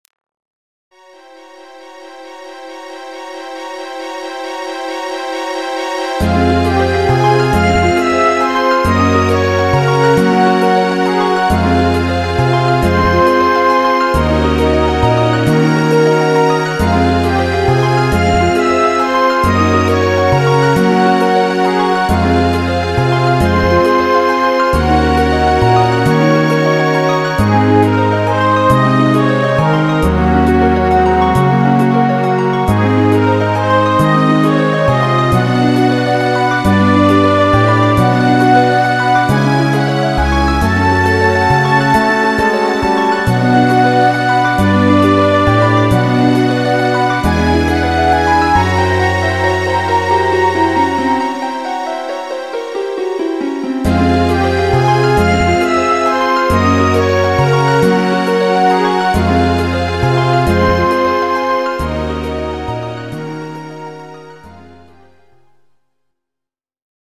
大好きだ！！GS音源。